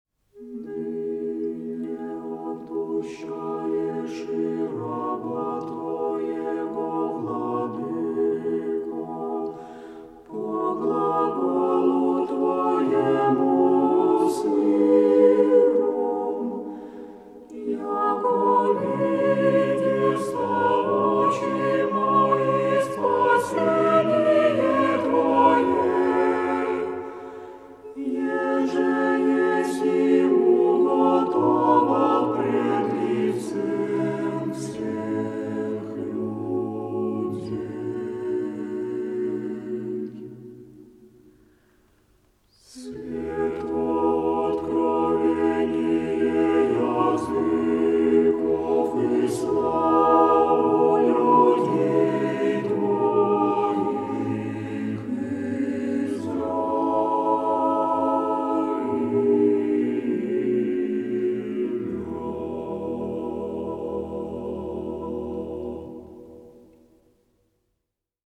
Предлагаем для прослушивания песнопения хорового коллектива.